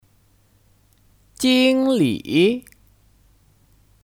经理 (Jīnglǐ 经理)